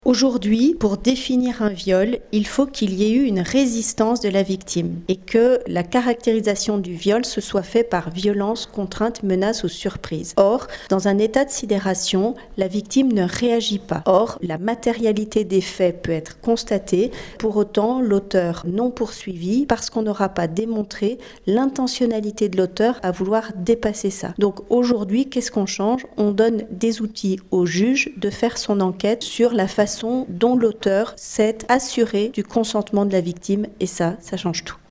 La députée d’Annecy Véronique Riotton, qui est également co-rapporteure du texte s’en est expliquée récemment en conférence de presse.